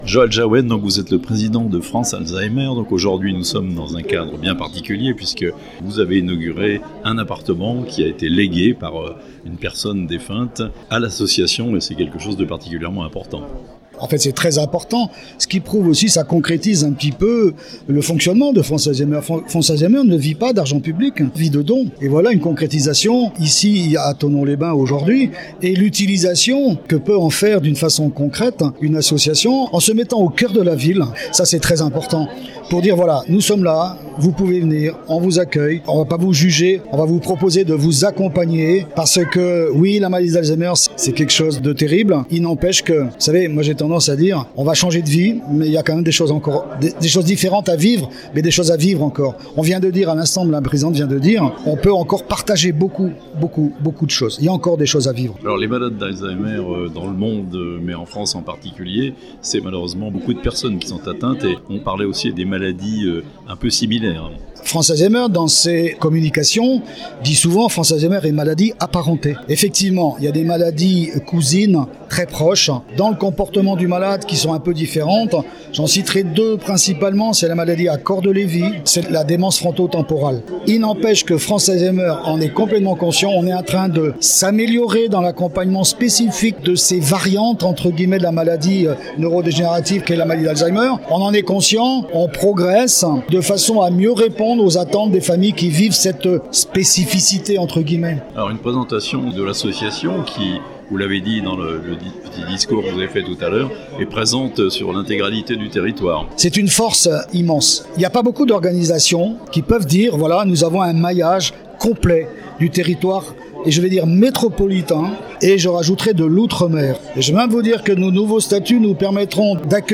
Un local en plein centre de Thonon pour l'association France Alzheimer (interview)